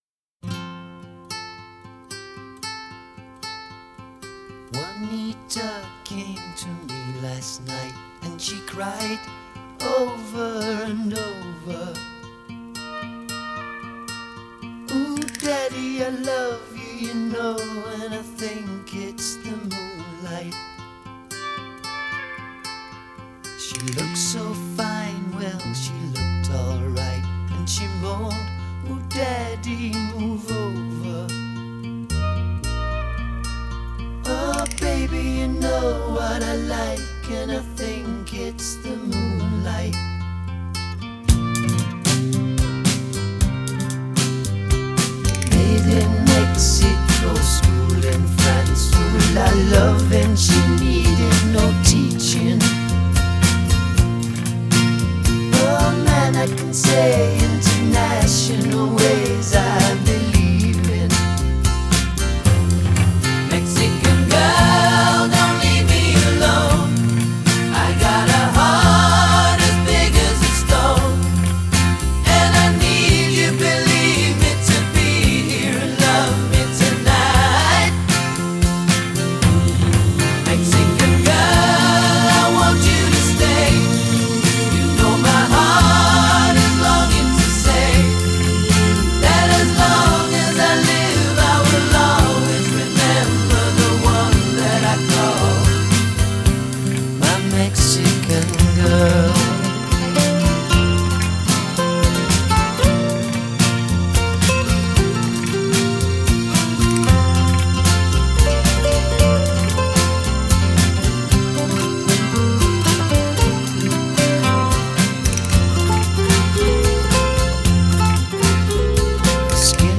Genre: Soft Rock